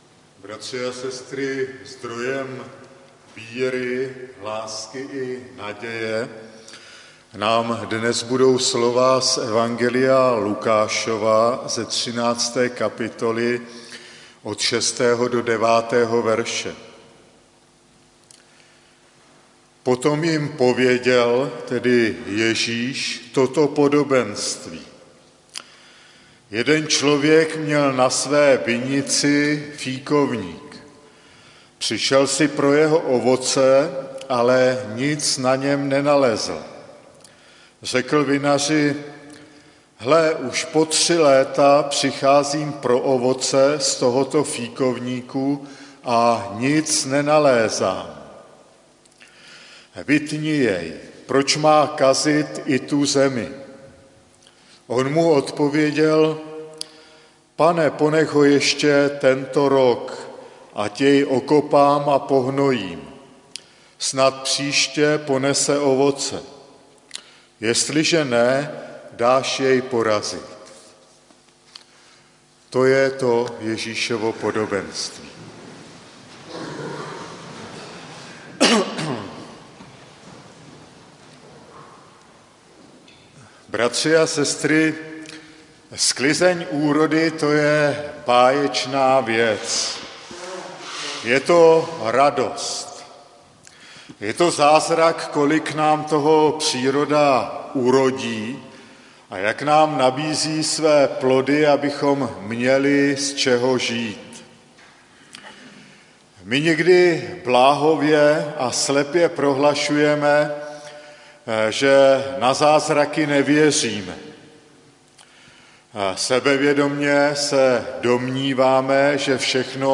Uložit kázání jako mp3 . Čtení: Kaz 5,17 – 19 Text: Lukáš 13,6 - 9 Sklizeň úrody je báječná věc.